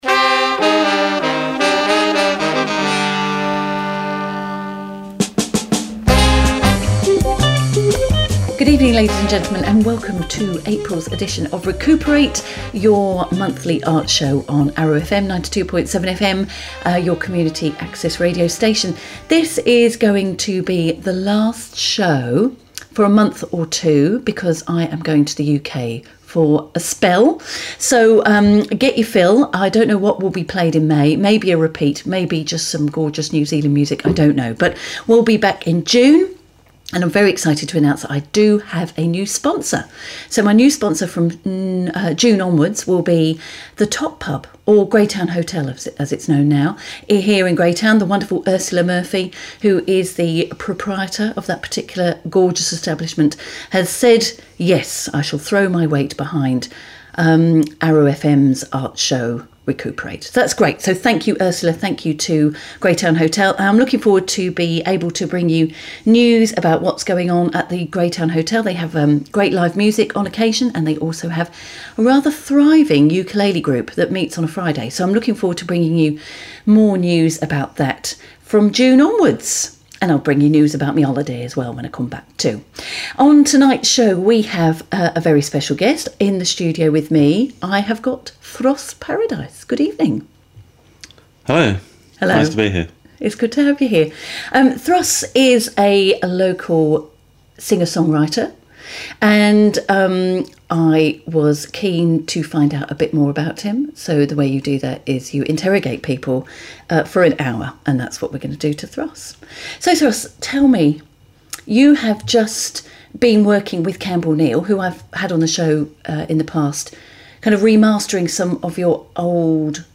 For those who can't wait till Sunday, here's the whole show!